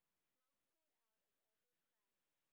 sp13_street_snr10.wav